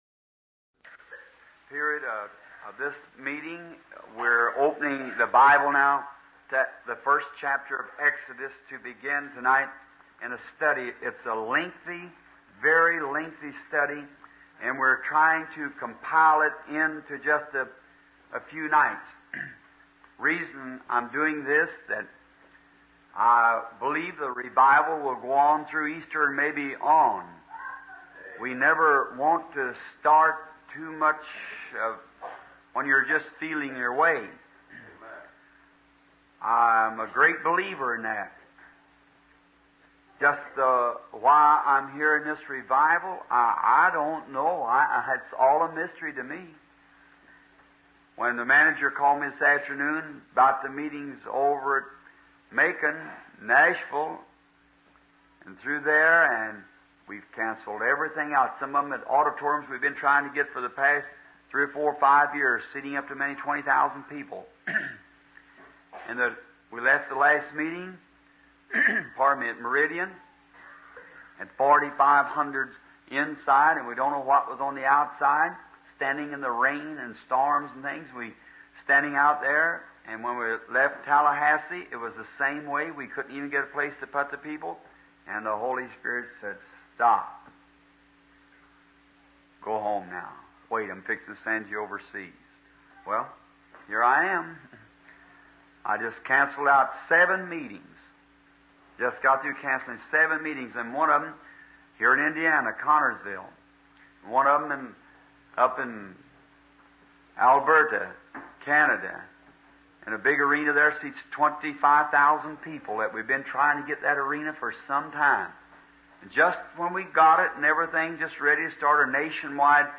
Dieses Portal gibt Ihnen die Möglichkeit, die ca. 1200 aufgezeichneten Predigten